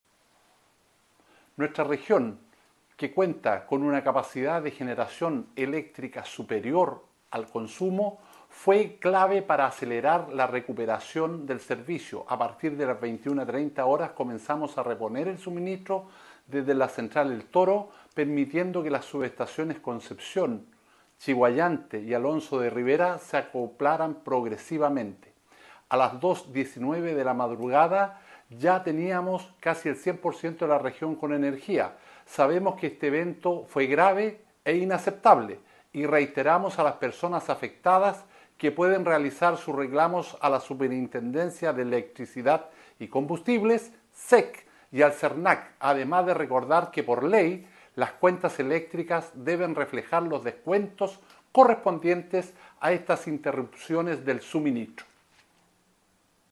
El seremi de Energía, Jorge Cáceres, valoró el trabajo coordinado que permitió esta pronta reposición.